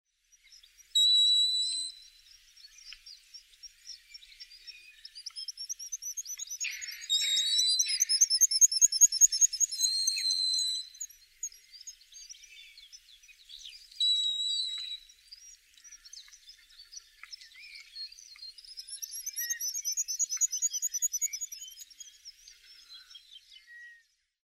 Black Guillemot  Cepphus grylle
Sonogram of Black Guillemot call and song
Portpatrick, Dumfries & Galloway, Scotland  54° 50' 32.62" N  5° 7' 9.75" W  14 May 2016
Piping calls (like peeeeu and seeeeo) at 3.9 to 4.2 kHz uttered from birds in nest holes of harbour wall.
High-pitched 'pipit-like' songs from displaying males (and more peeeeu and seeeeo calls)